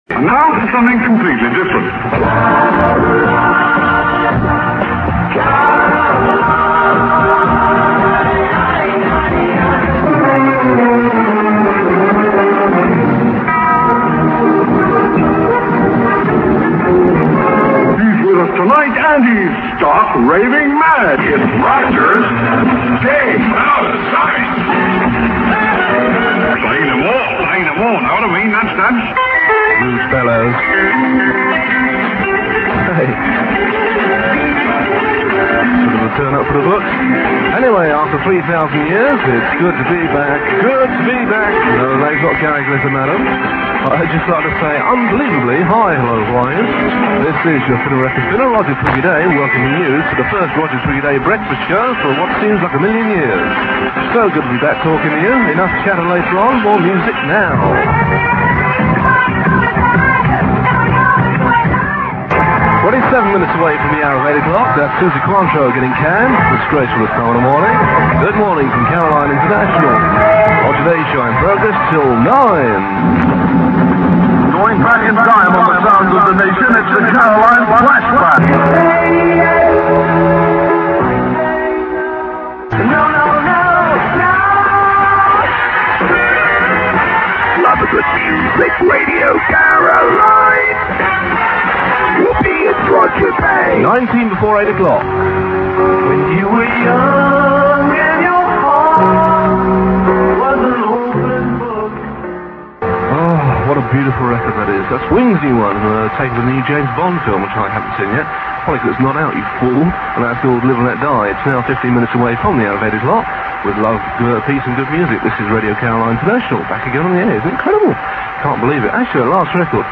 click to hear audio Roger Day's first and only Breakfast Show on the new Radio Caroline International, 26th June 1973 (duration 3 minutes 20 seconds)
Roger's programme was pre-recorded on land.